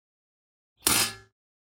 Металлическую крышку резко захлопывают